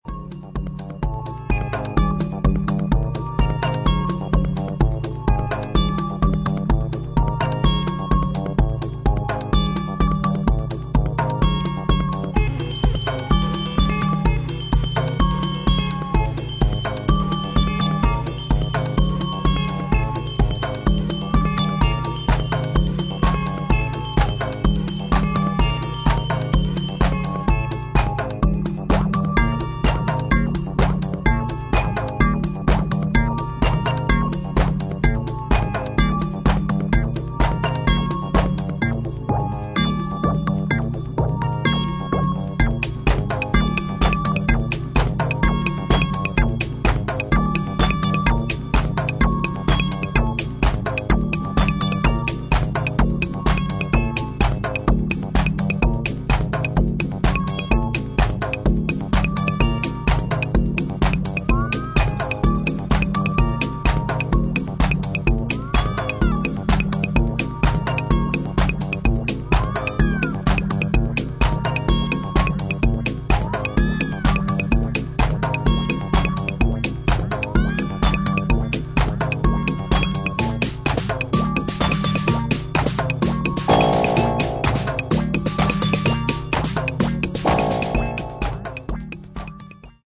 ハードウェアシンセサイザーの力を思う存分に振るったアンビエントテクノを披露